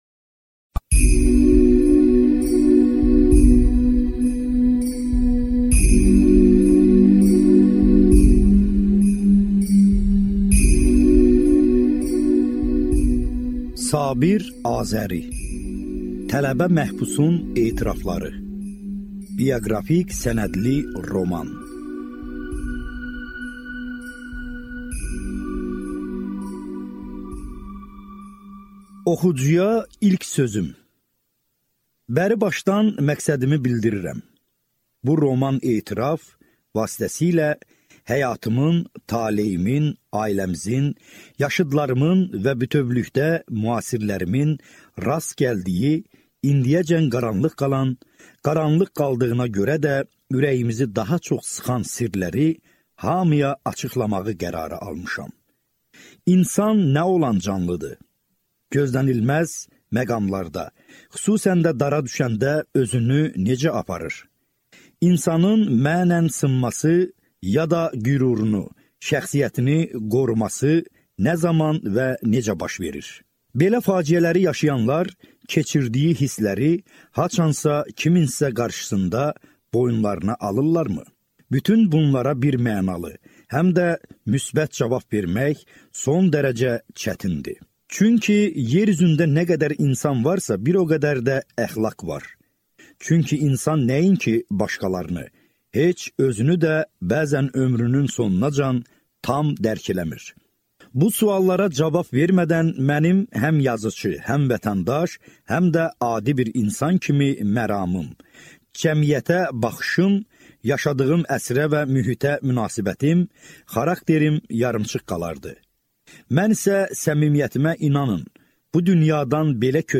Аудиокнига Tələbə məhbusun etirafları | Библиотека аудиокниг